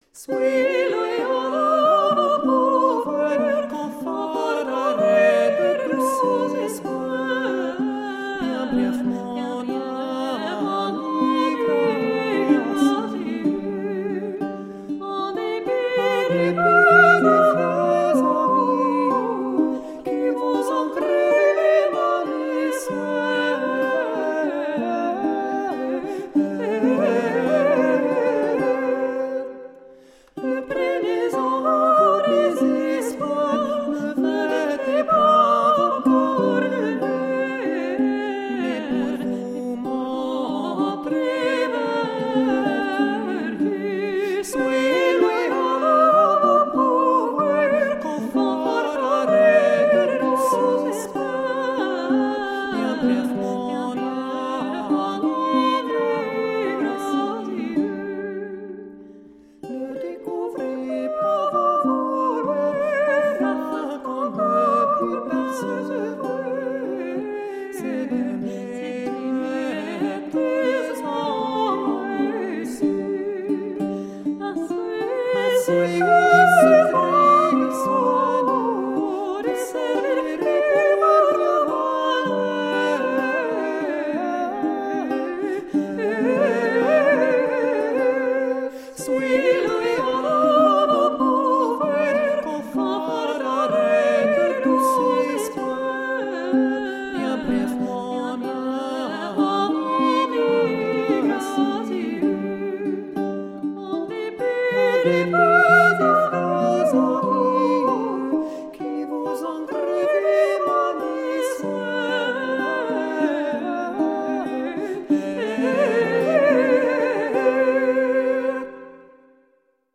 Late-medieval vocal and instrumental music.